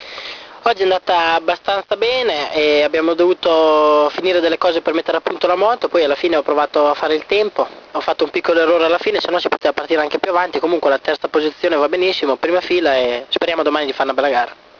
Parlano i protagonisti della 500 dopo le pole position